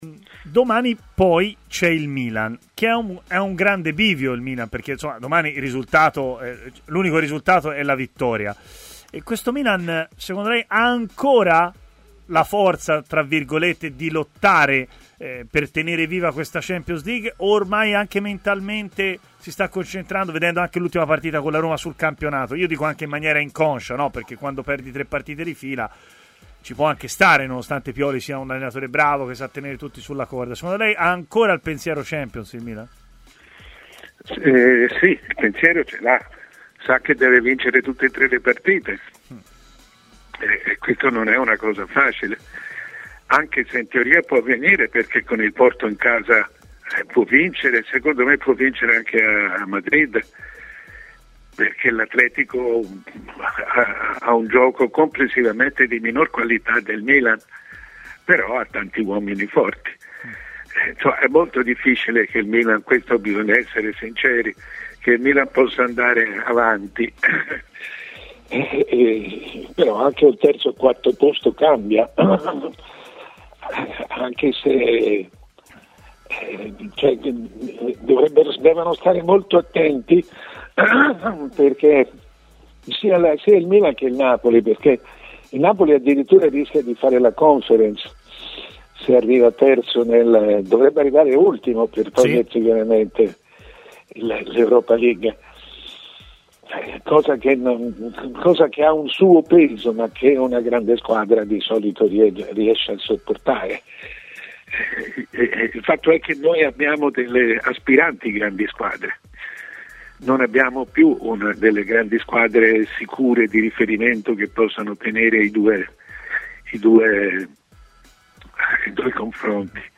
L'opinionista Mario Sconcerti, prima firma del giornalismo sportivo italiano, ha parlato ai microfoni di Tmw Radio.